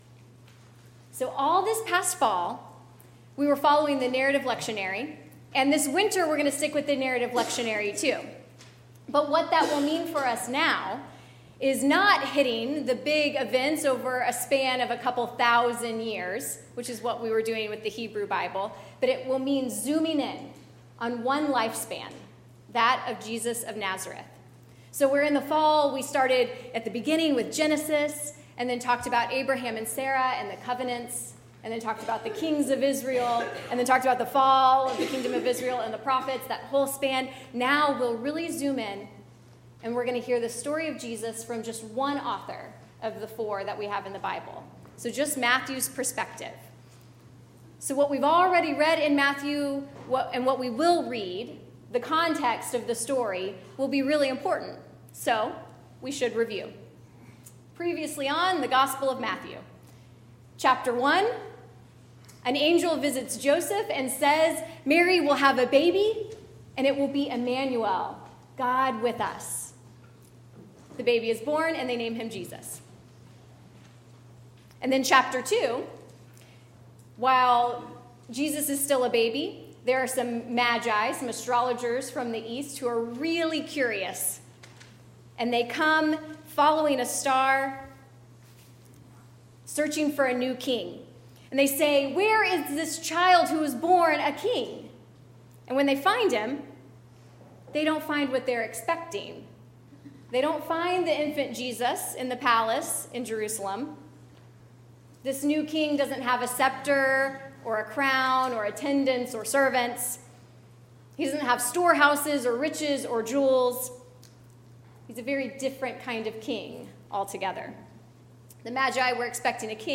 sermon-2019-01-13.mp3